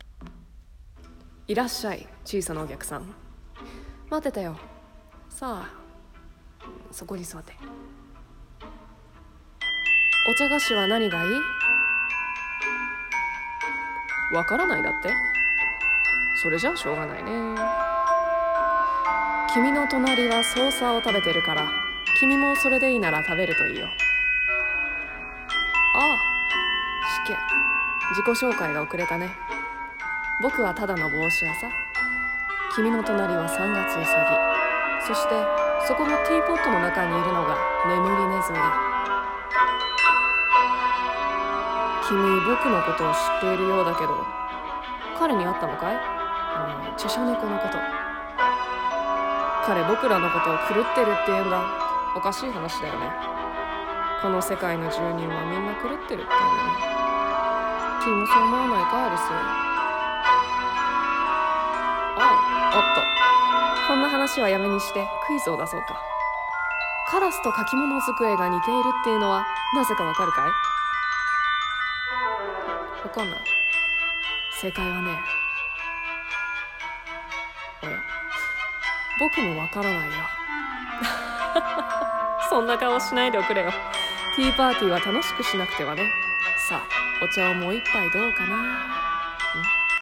声劇 A Mad Tea-Party